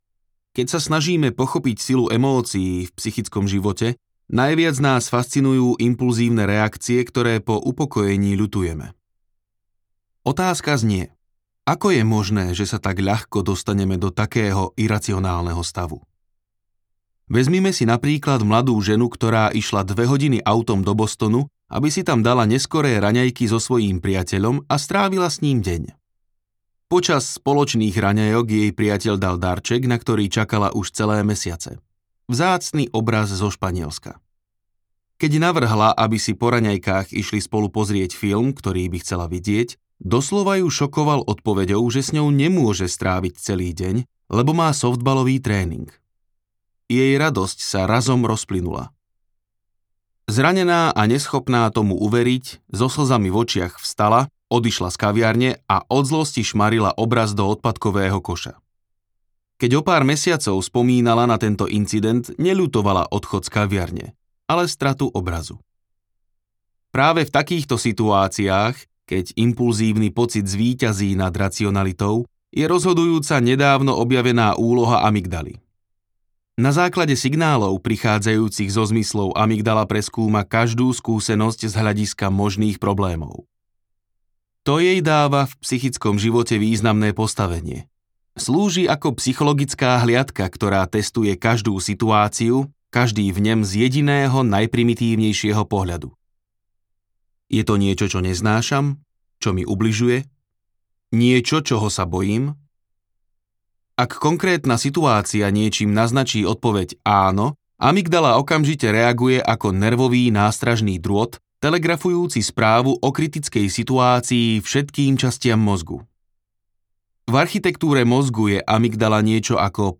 Emocionálna inteligencia audiokniha
Ukázka z knihy
emocionalna-inteligencia-audiokniha